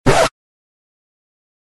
missnote2.mp3